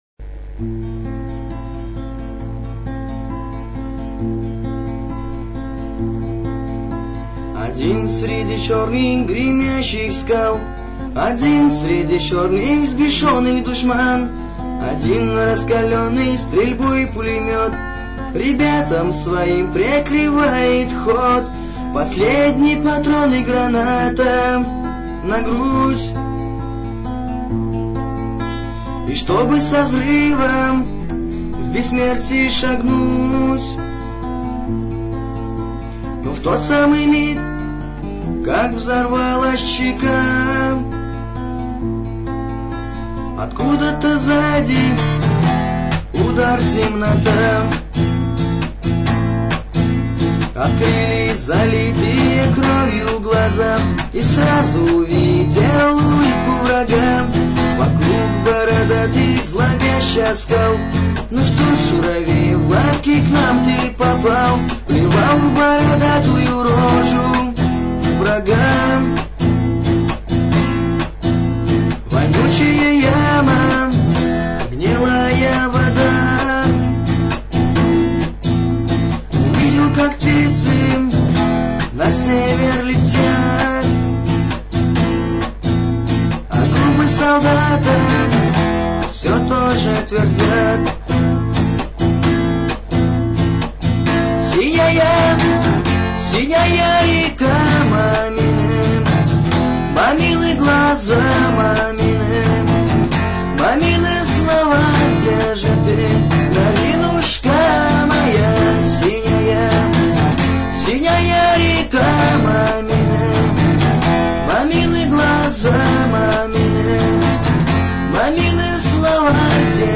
pesni_pod_gita_sinaa_reka.mp3